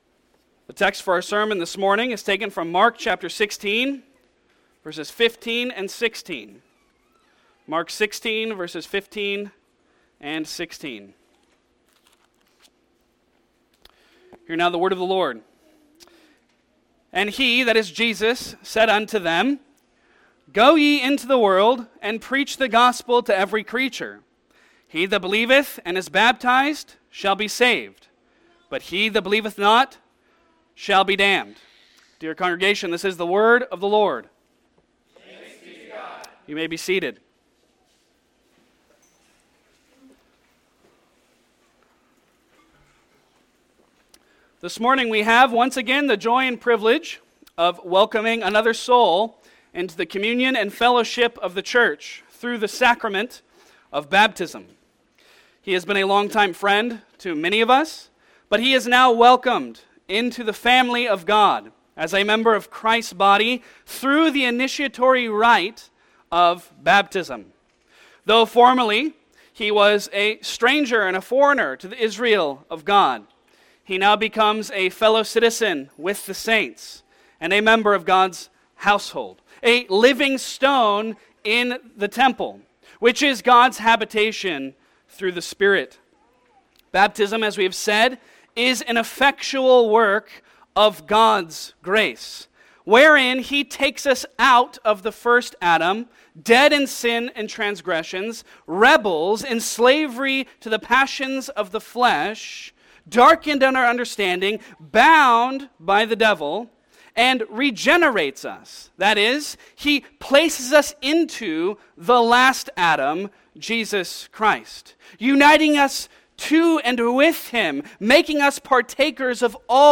Service Type: Sunday Sermon Topics: Baptism